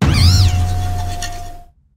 brutebonnet_ambient.ogg